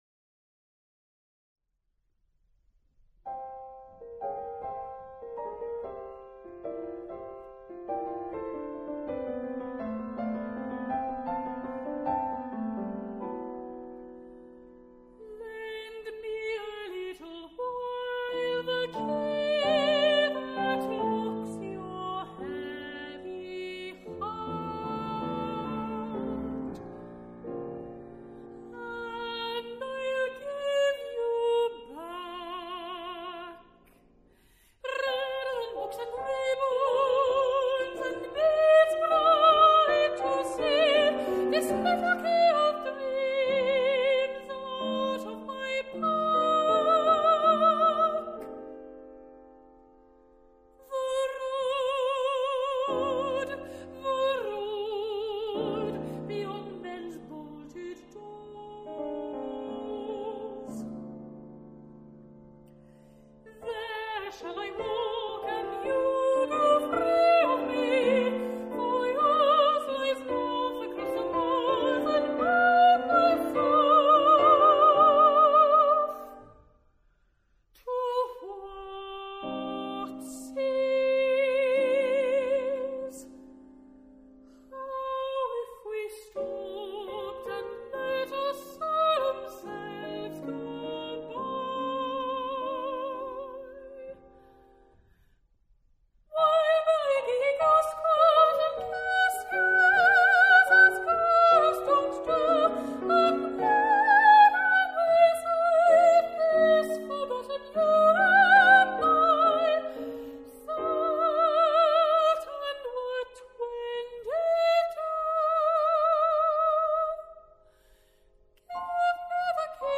soprano
piano